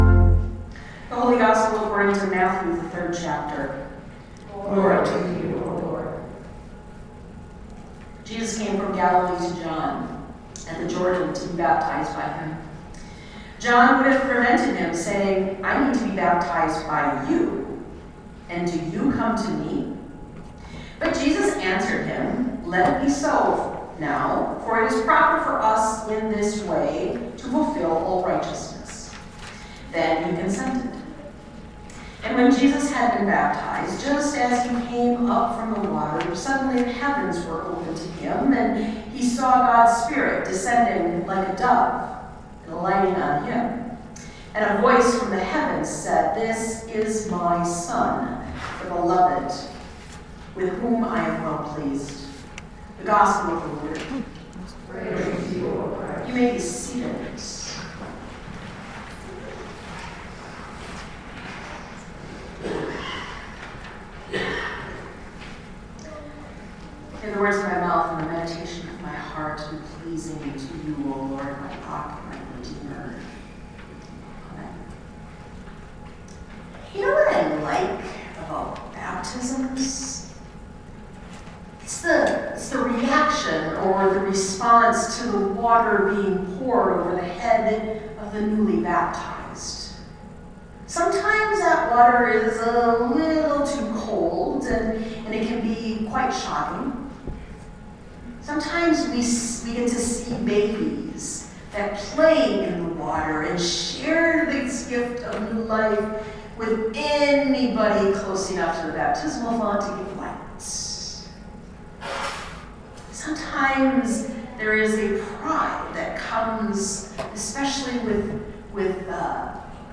Sermons by United Lutheran Church